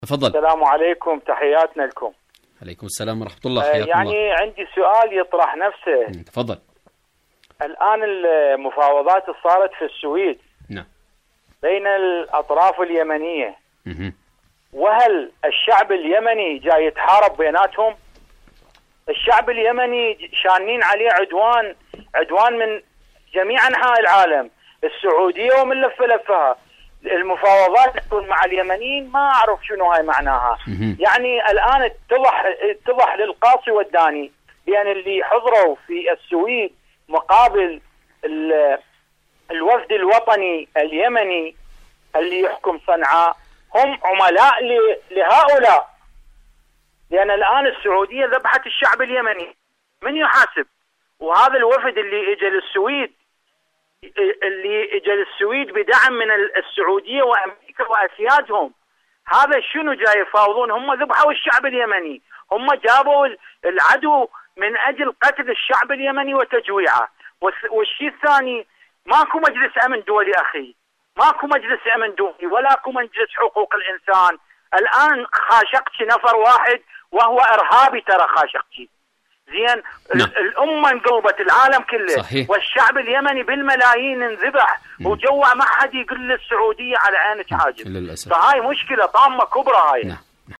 برنامج: اليمن التصدي و التحدي / مشاركة هاتفية